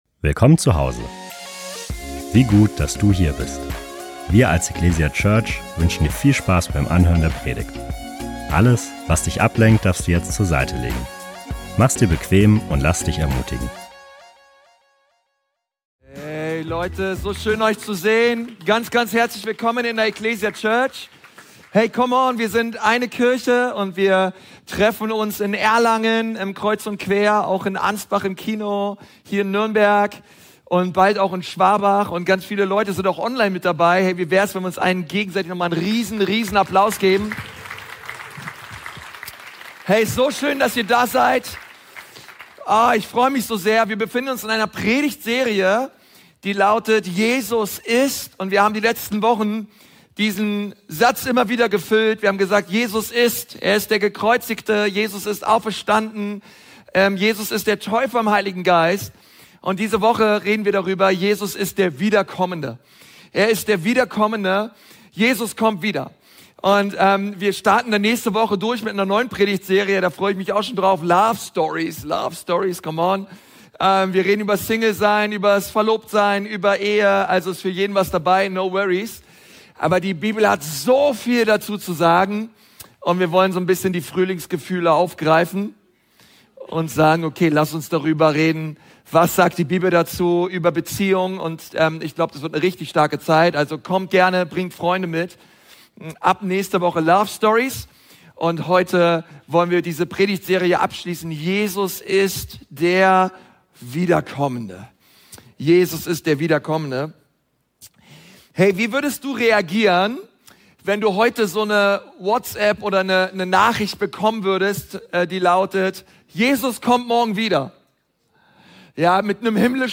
Die Bibelstellen zur Predigt und eine Möglichkeit aktiv mitzuschreiben, findest du in der digitalen Predigtmitschrift.